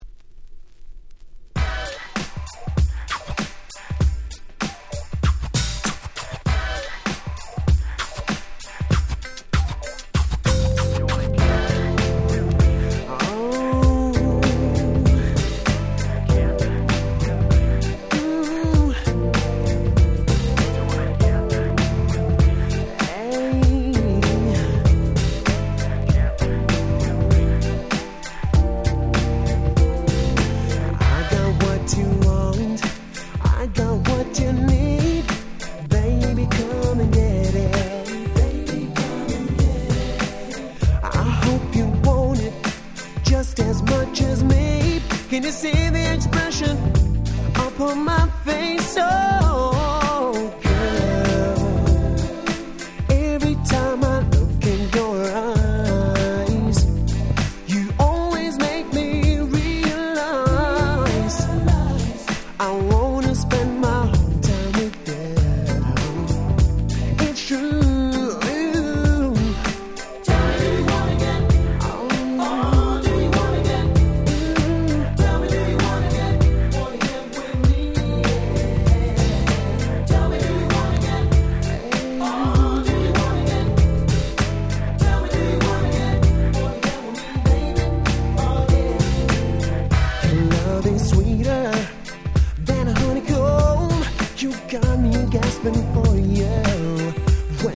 96’ Super Nice UK Street Soul / R&B Compilation !!
ずっしりとした所謂「ADMビート」の上で美メロな甘い歌声を堪能する事が出来る必殺ナンバー！！